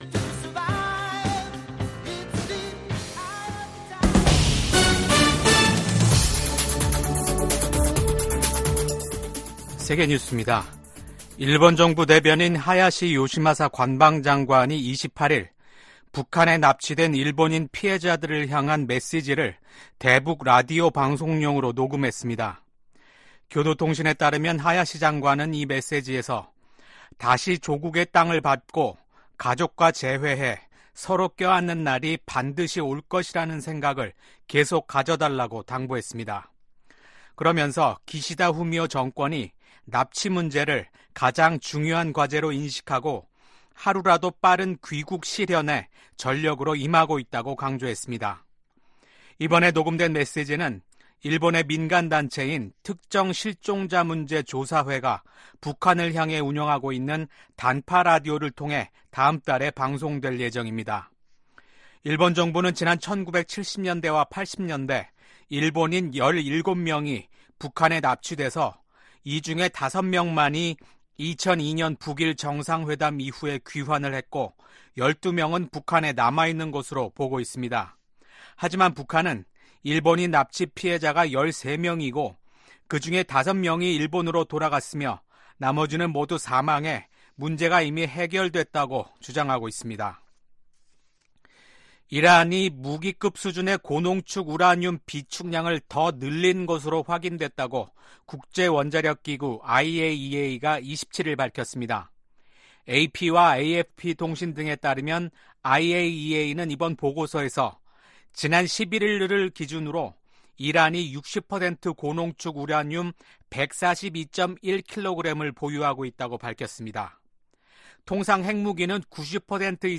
VOA 한국어 아침 뉴스 프로그램 '워싱턴 뉴스 광장' 2024년 5월 29일 방송입니다. 북한이 27일 밤 ‘군사 정찰위성’을 발사했지만 실패했습니다.